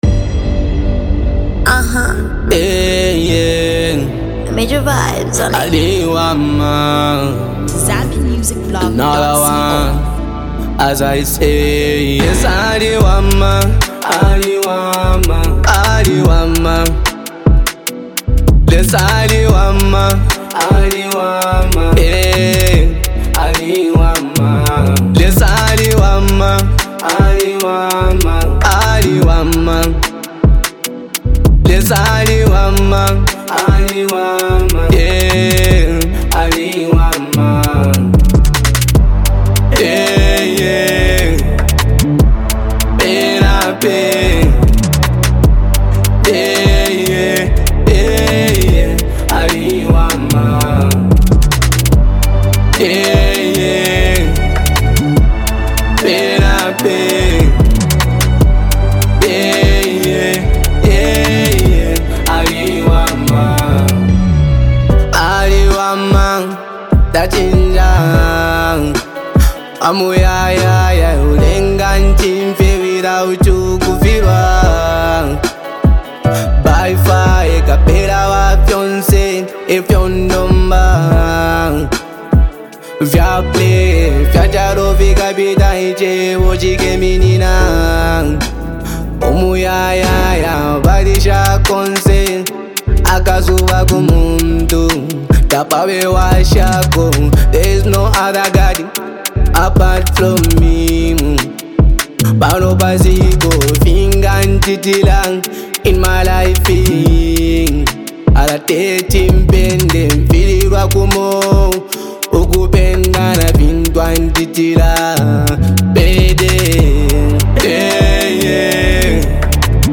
is a soulful and upbeat track
gospel music